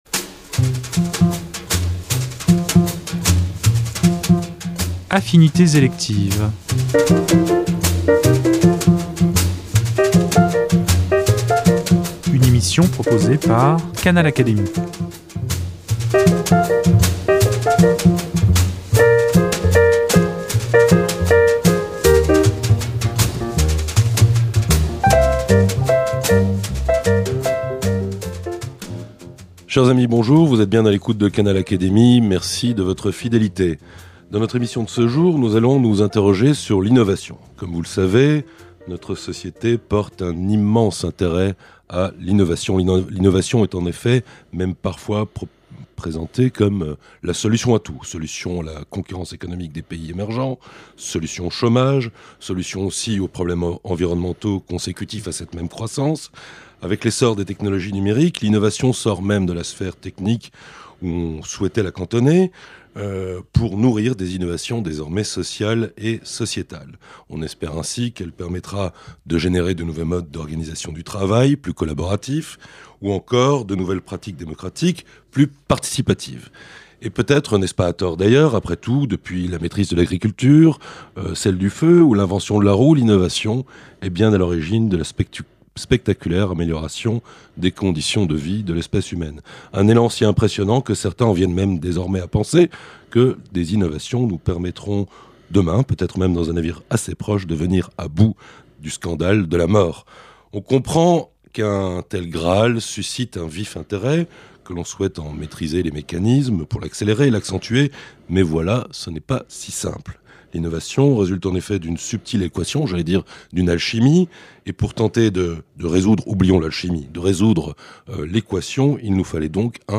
la leçon inaugurale qu’il a prononcée au Collège de France